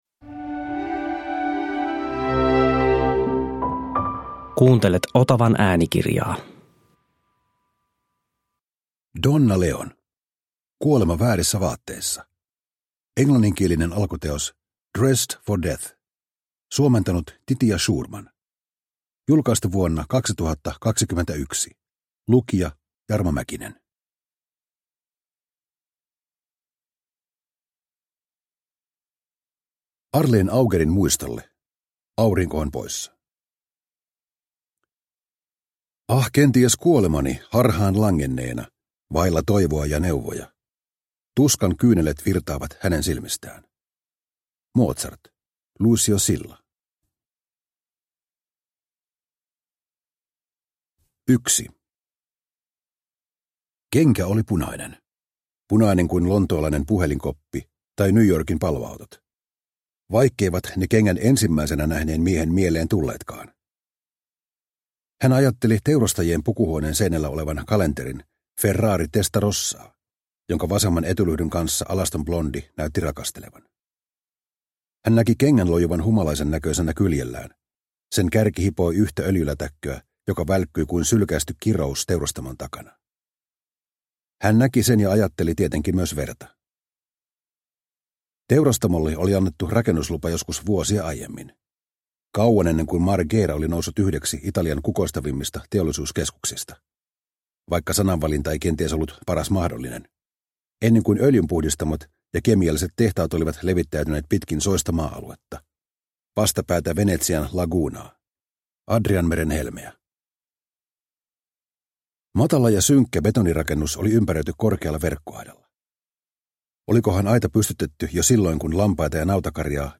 Kuolema väärissä vaatteissa – Ljudbok – Laddas ner